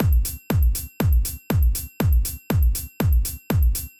Drumloop 120bpm 04-C.wav